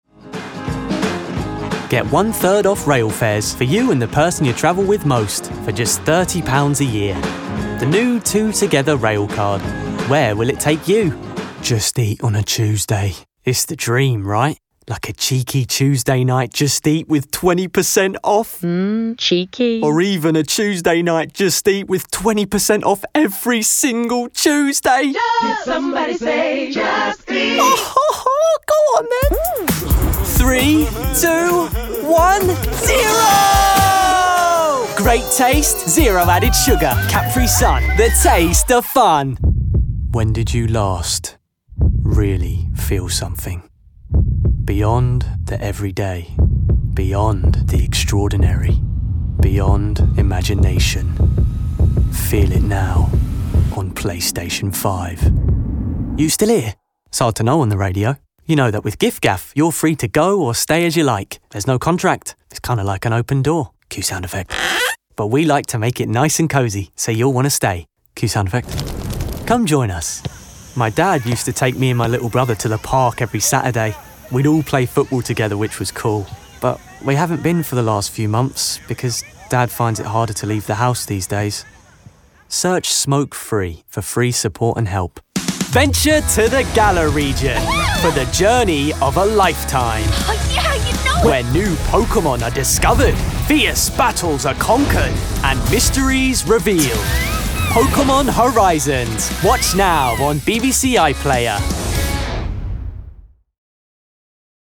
with a warm and youthful sound.
Commercial
Teens-30s - fresh, energy, character